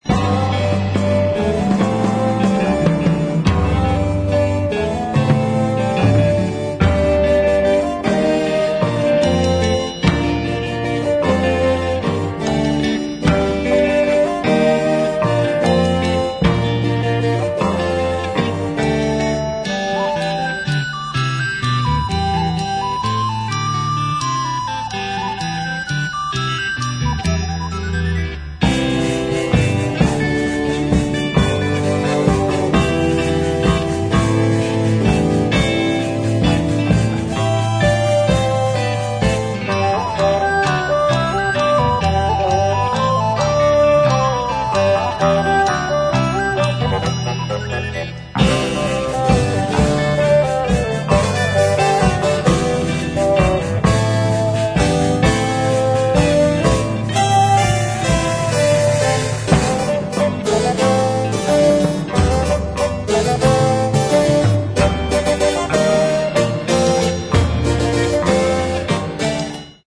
Mono, 1:09, 32 Khz, (file size: 273 Kb).